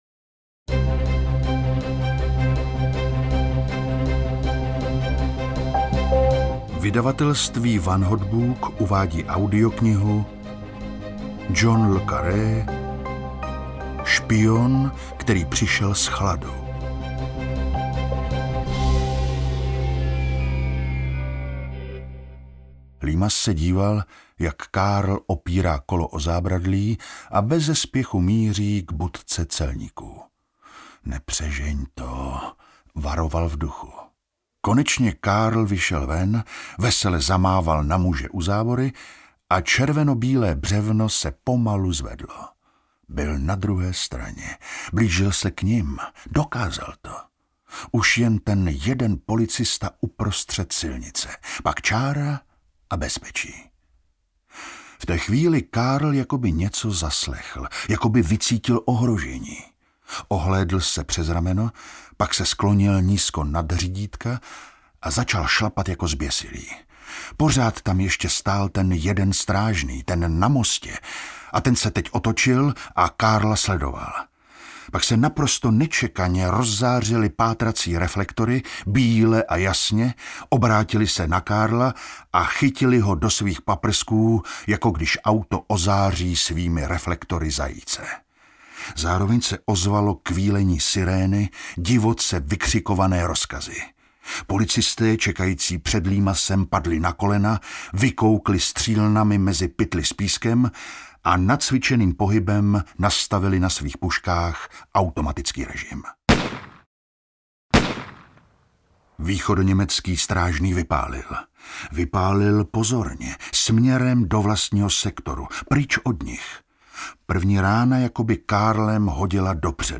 Špion, který přišel z chladu audiokniha
Ukázka z knihy